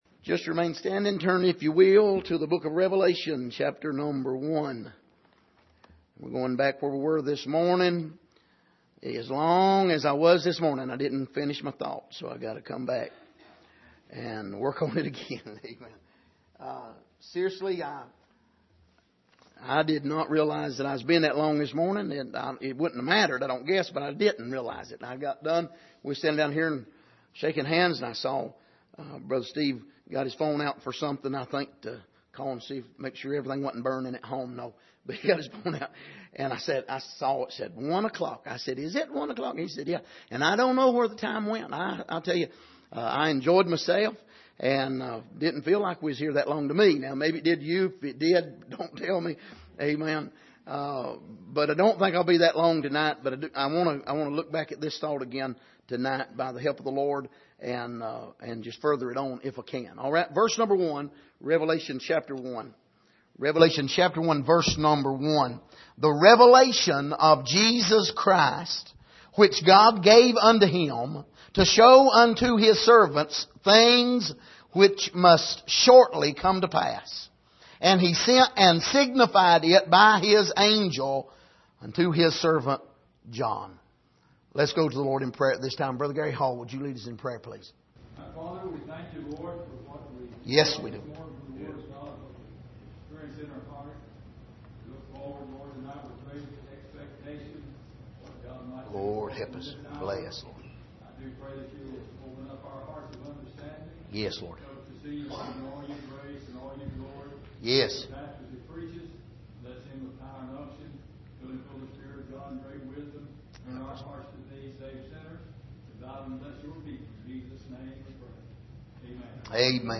Passage: Revelation 1:1 Service: Sunday Evening